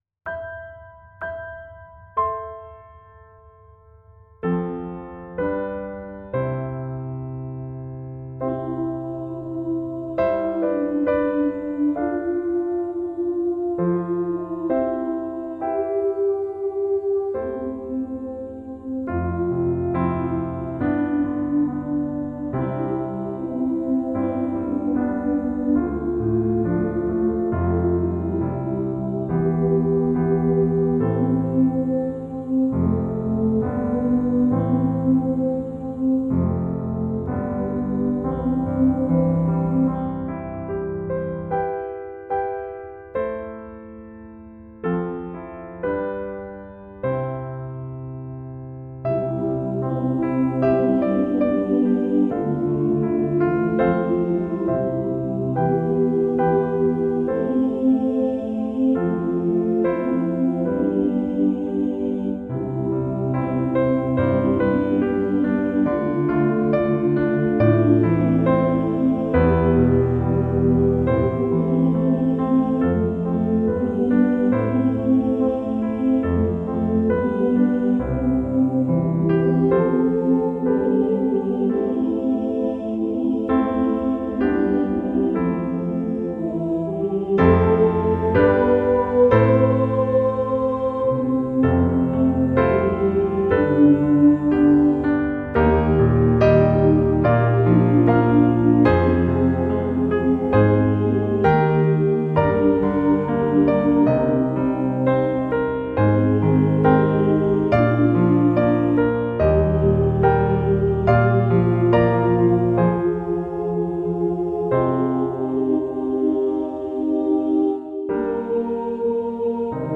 SAB (Key of C)